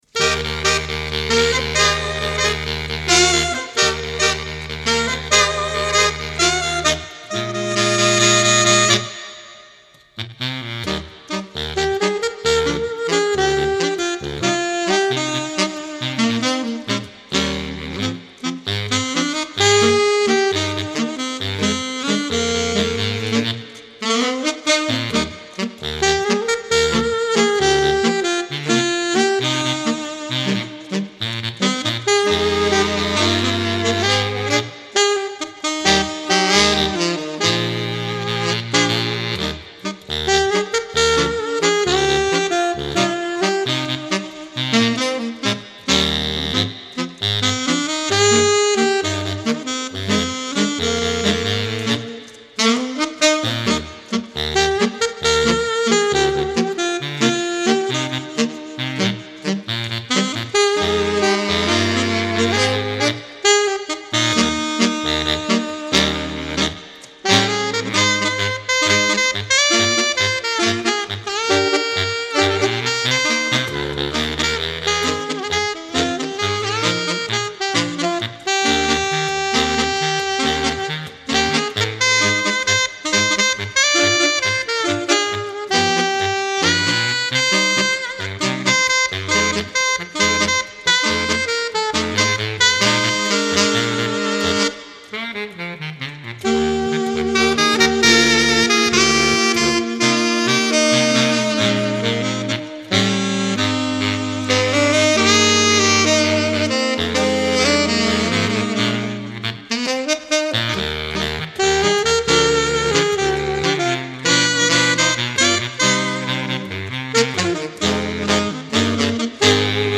Ranges: All saxes stay between Bb1 and F3.